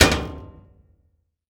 Washing Machine Door Sound
household